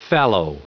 Prononciation du mot : fallow